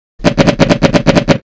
Machine Gun
Bangs Gun Gunshots Shooting Warzone sound effect free sound royalty free Gaming